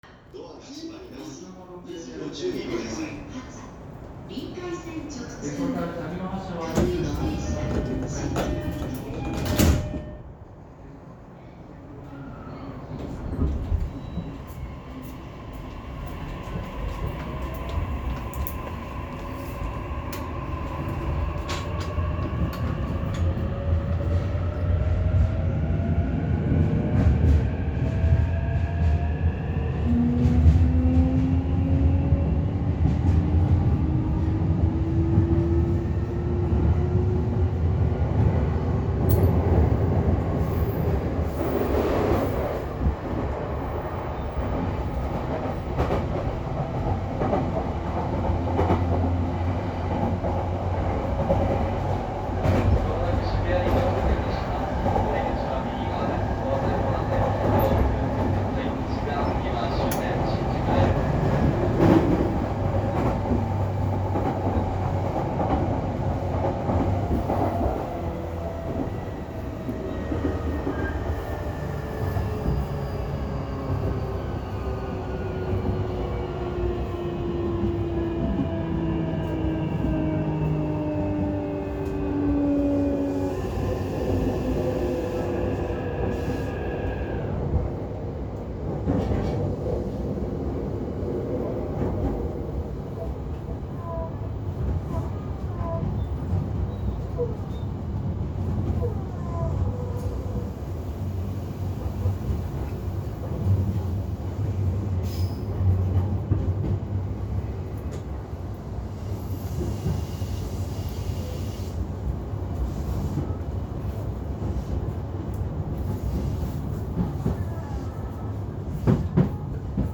・3000番台 走行音
【湘南新宿ライン】恵比寿→渋谷
3000番台だけは三菱ではなく日立のモーターを採用しているので音が全く異なっています。E531系とほぼ同じ音です。